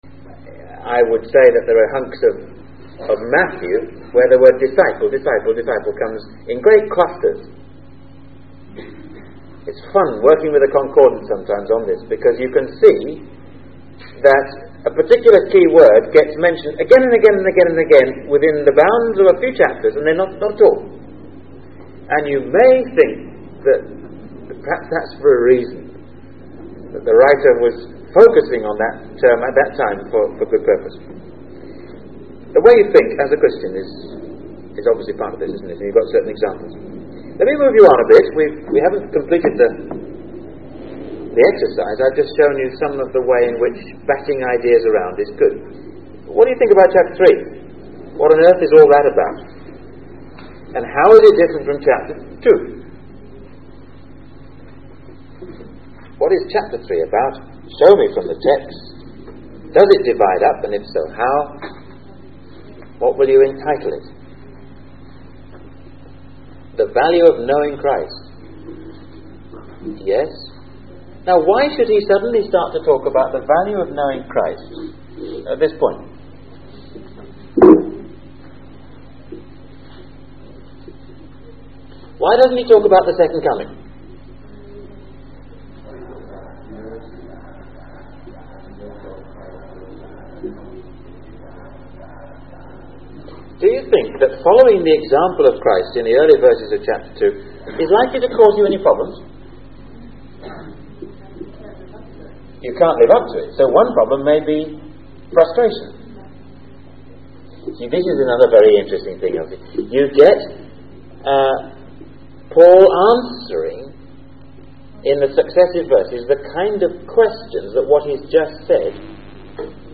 In this sermon, the speaker discusses the mind of the Christian worker and how they should cope with the challenges of serving and following Christ. The speaker suggests that the whole chapter 4 of the Bible is about different ways of thinking.